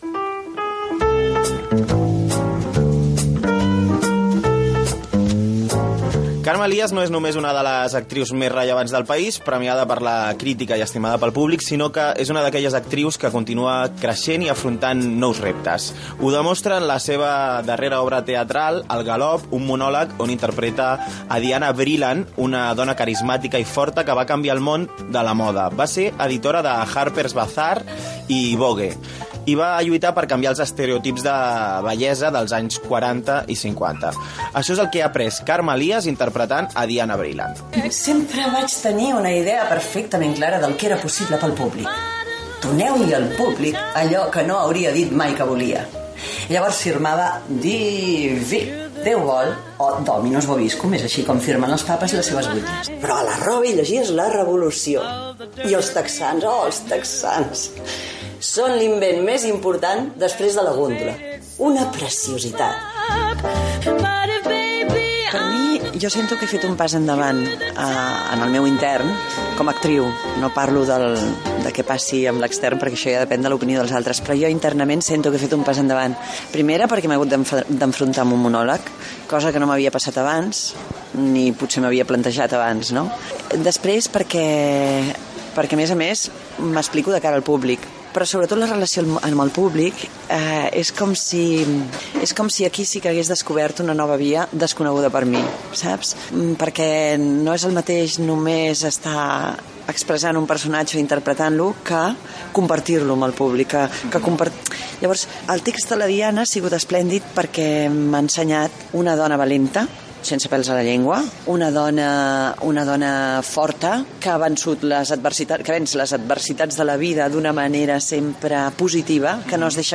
Entrevista a l'actriu Carme Elias que actua a l'obra "Al galop"